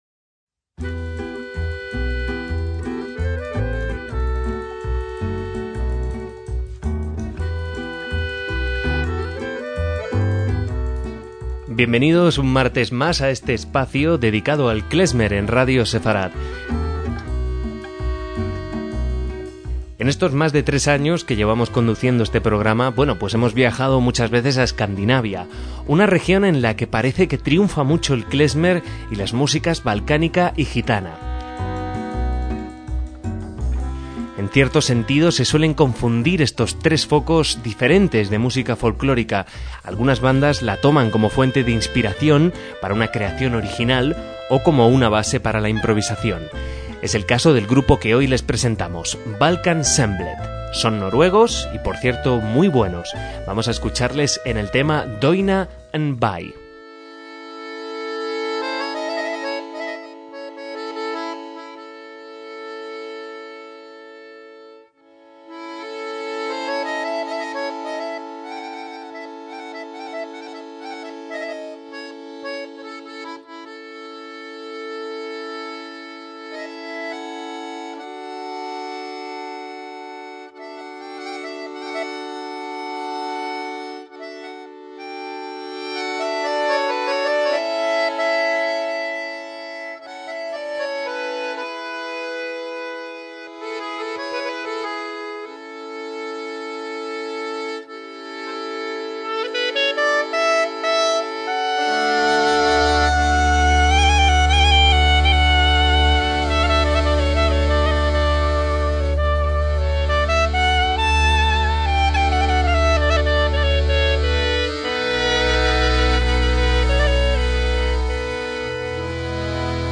MÚSICA KLEZMER
percusión
acordeón, bouzouki y balalaika
clarinete y flauta